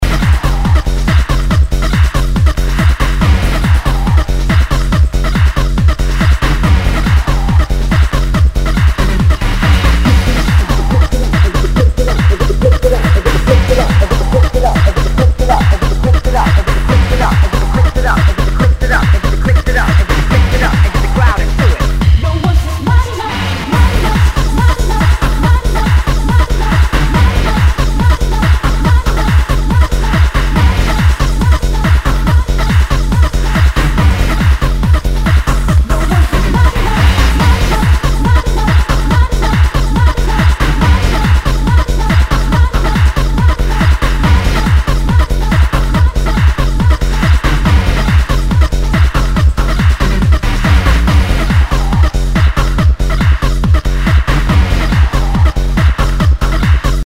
HOUSE/TECHNO/ELECTRO
ナイス！ハード・ハウス！
盤に傷あり全体にチリノイズが入ります